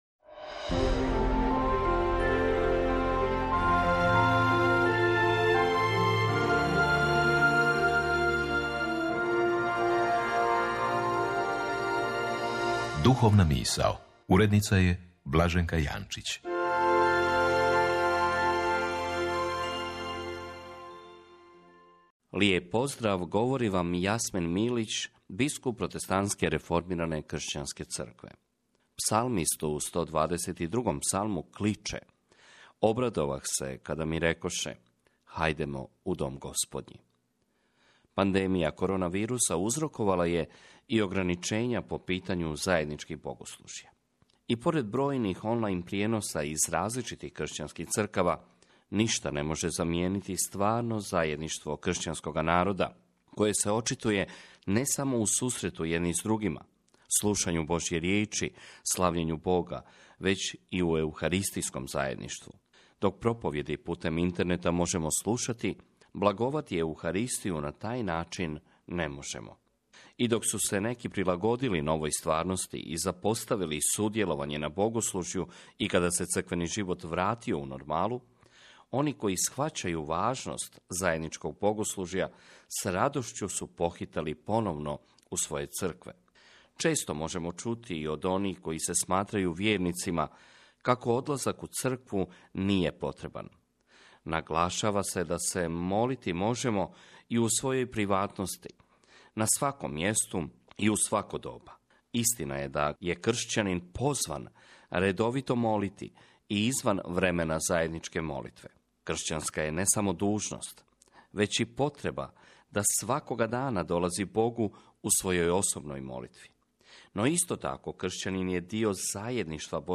Autor: Biskup Jasmin Milić
Iz emisije Hrvatskog radija “Duhovna misao”.